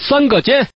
Man_tuple1.mp3